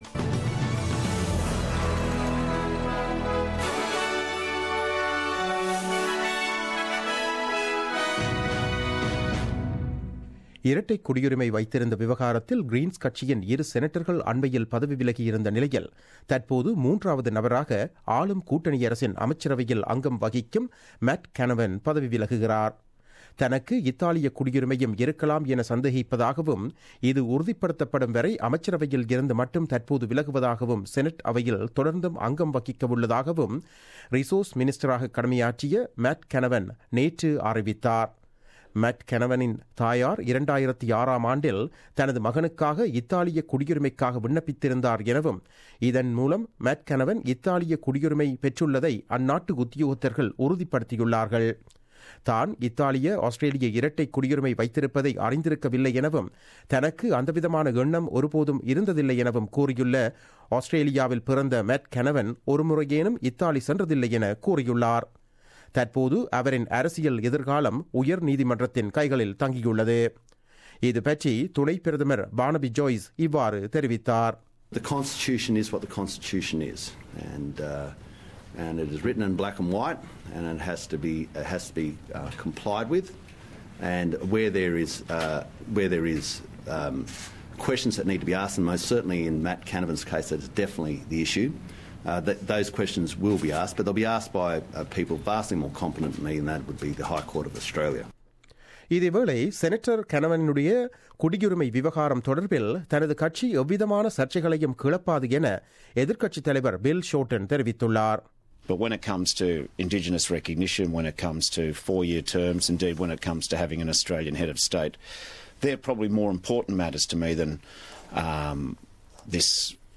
The news bulletin broadcasted on 26 July 2017 at 8pm.